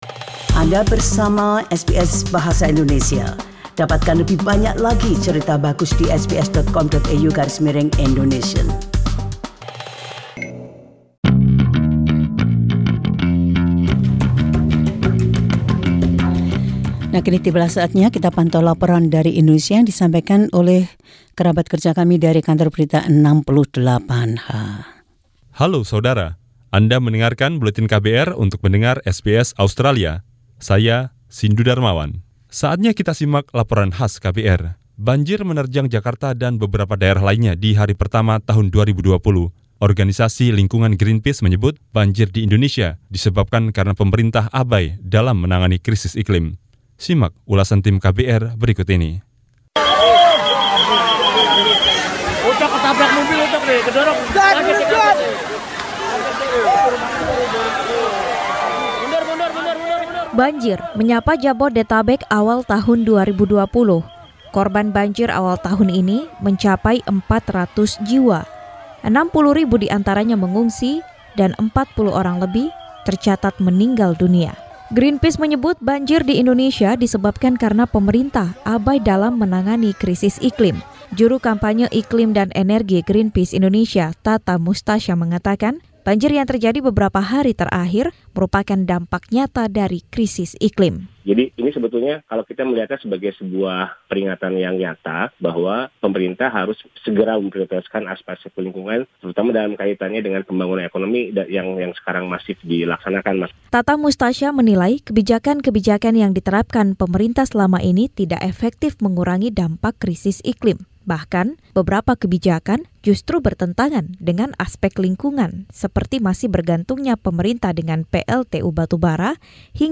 Laporan KBR 68H.